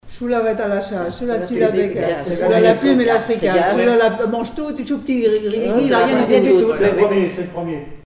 formulette enfantine : amusette
Comptines et formulettes enfantines